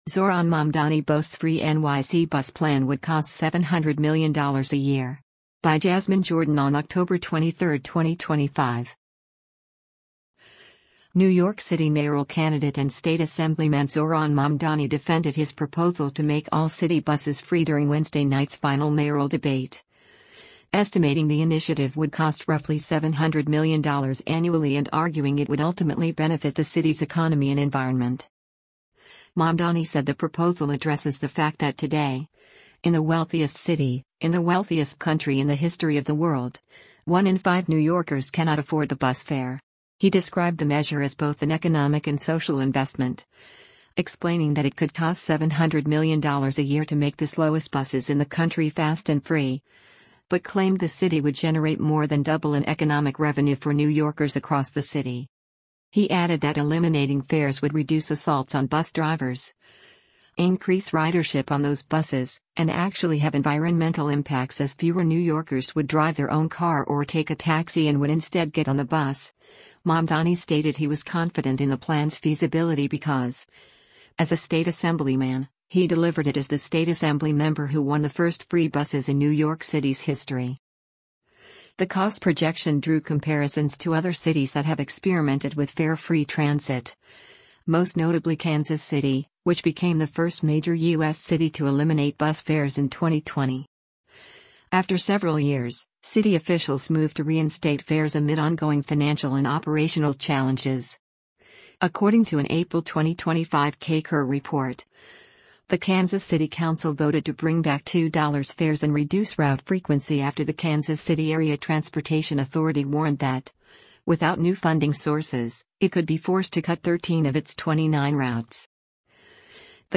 Democratic mayoral nominee Zohran Mamdani speaks during a mayoral debate at Rockefeller Ce